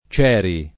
— freq. incertezze nella pn. dell’ -e- , dovute a cera (e a cero ) — il cogn., in alcune famiglie, nella var. grafica Cieri — cfr.